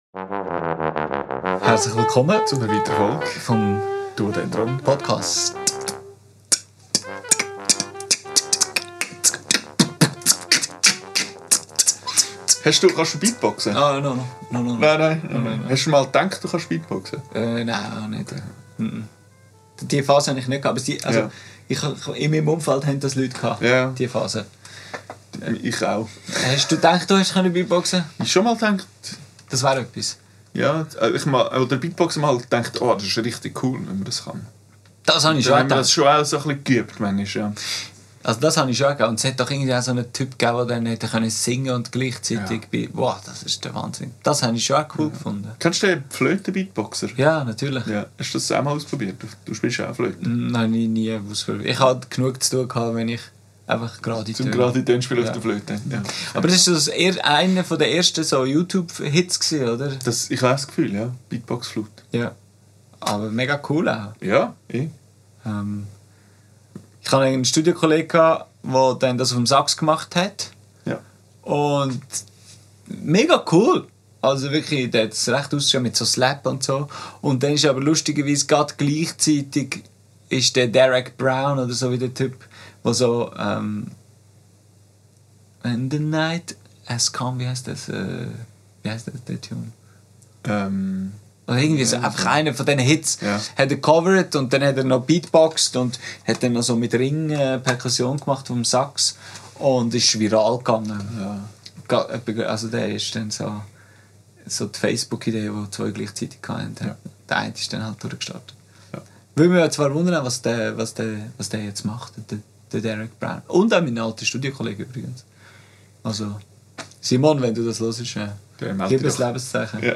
Was ist denn ein Saxophon-Slap? Geht das auf der Posaune auch? Wird es bald Long-Tone Posaunen-Events geben? Aufgenommen am 23.10.2025 im Atelier